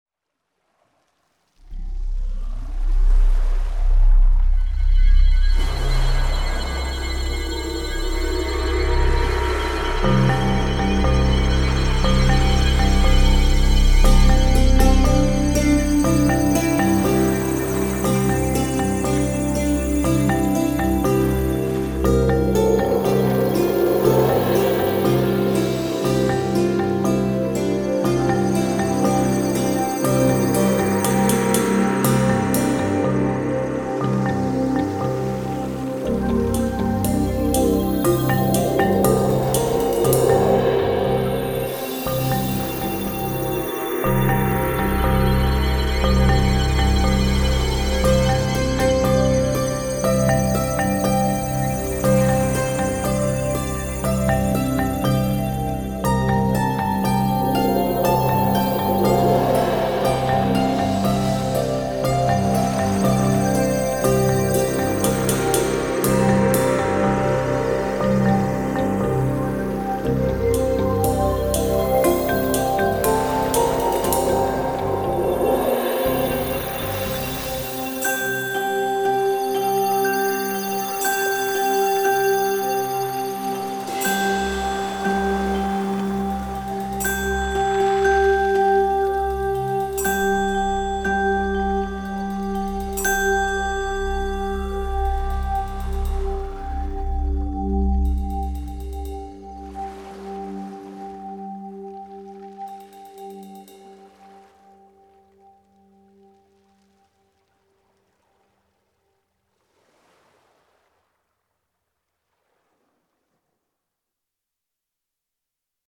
gentle, ethereal and haunting style. Backing track.